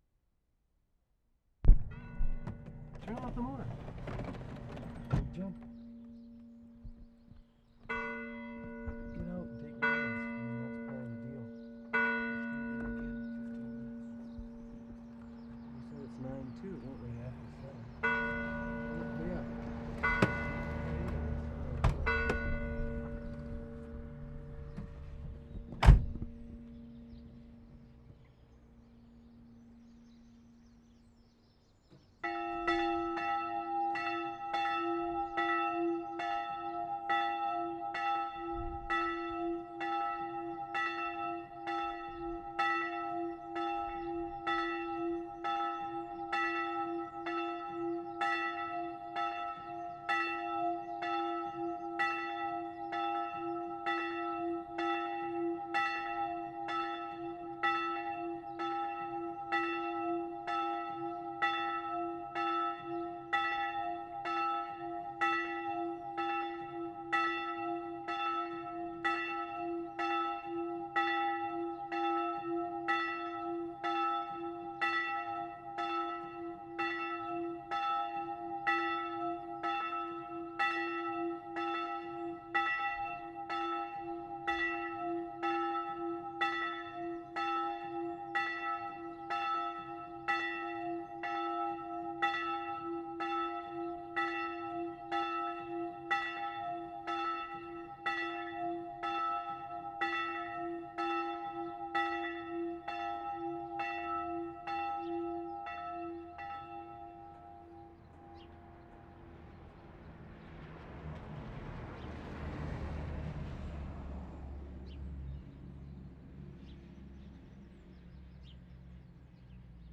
WORLD SOUNDSCAPE PROJECT TAPE LIBRARY
Lesconil, France April 17/75
CATHOLIC CHURCH BELLS, single bell ring.
2. Beginning marred by talk and van door slamming.